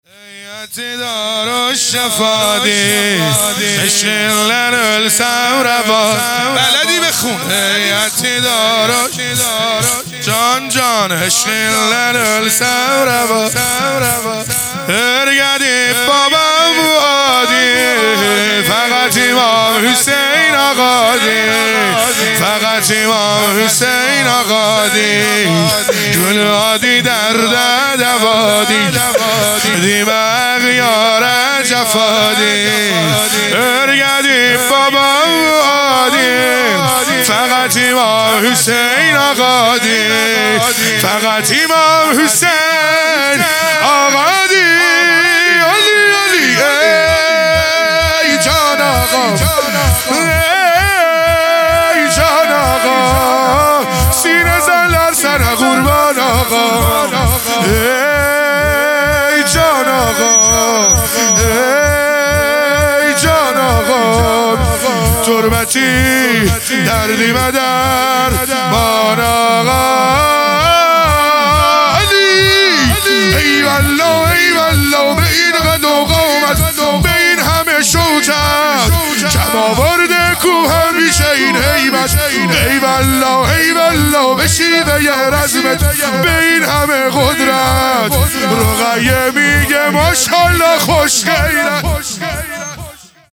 شهادت حضرت زینب (س) | 27 بهمن ماه 1400 | شور | هیئتی داروالشفادی